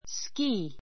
skíː ス キ ー